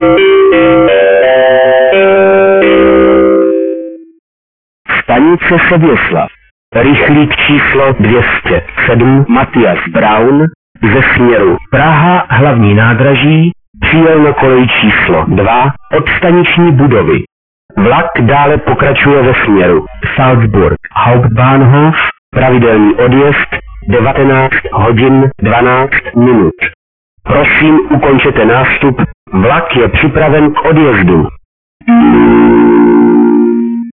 Nevim jak to zmenšujete (po vyčištění v editoru to mělo 3 mega) tak jsem to udělal mono.